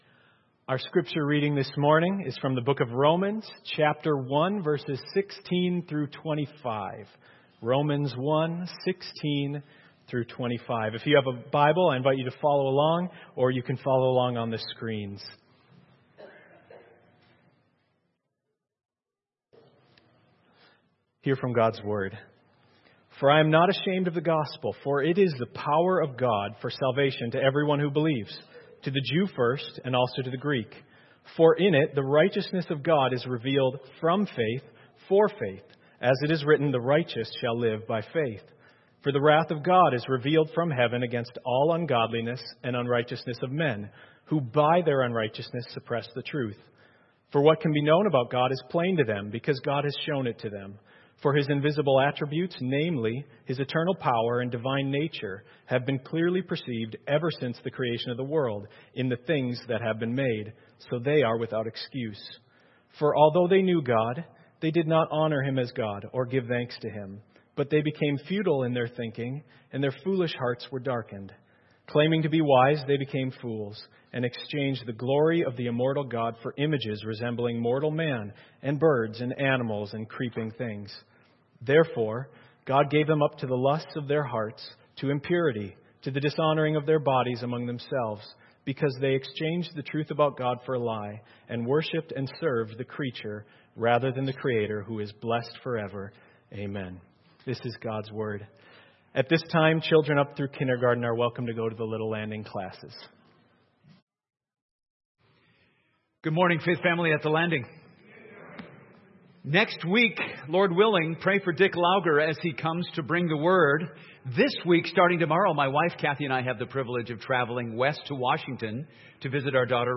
Sermons | The Landing Church